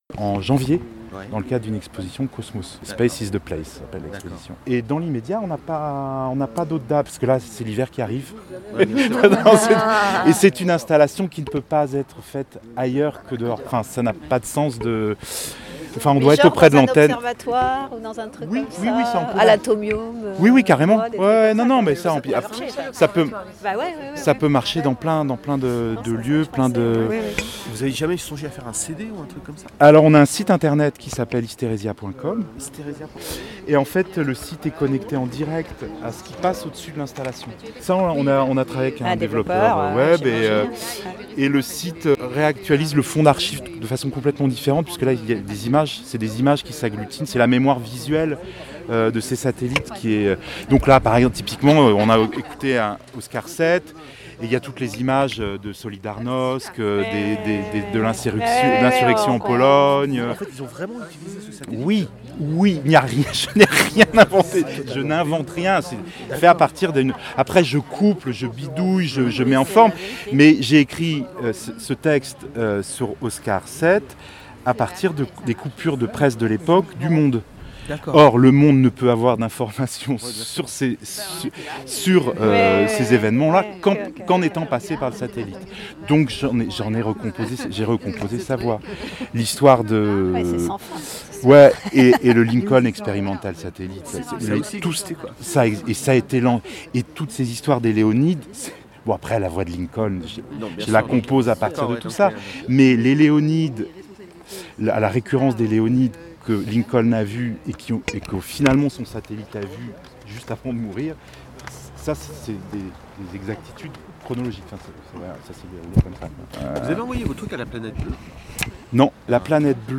Discussions et interviews Hystérésia (6.85 Mo)